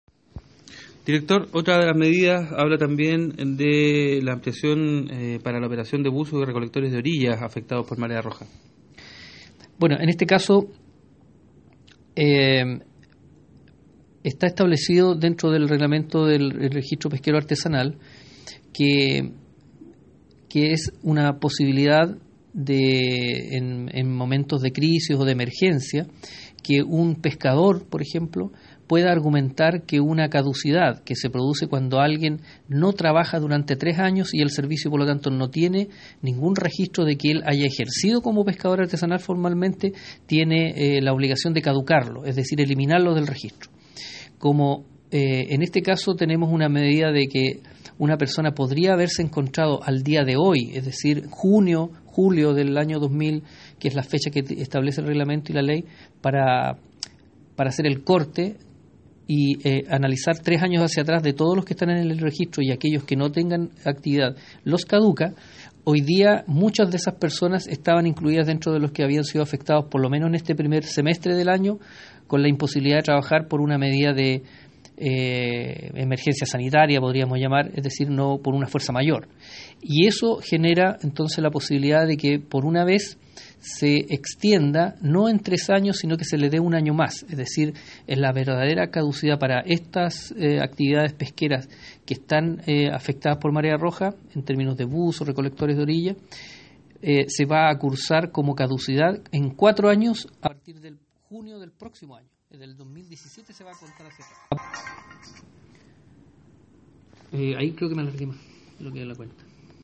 Juan Fermín, Director Zonal de Pesca Región de Los Lagos